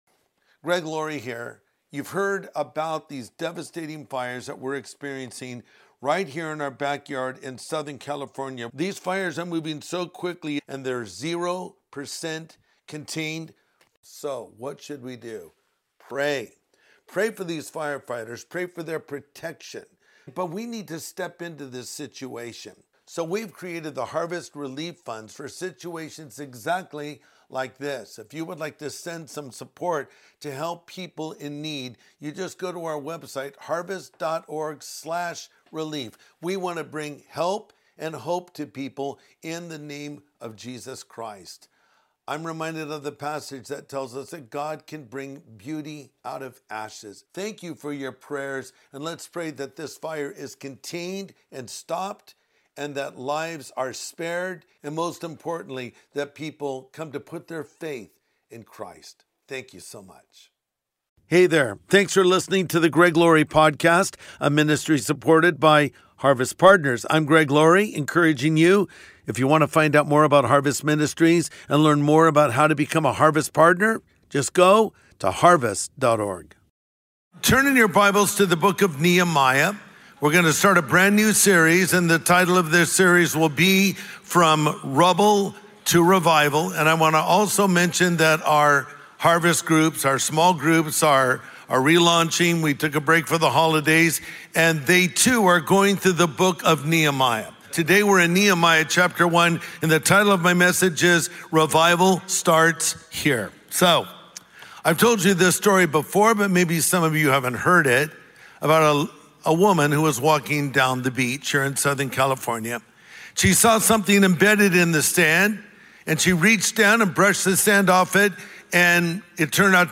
Revival Starts Here | Sunday Message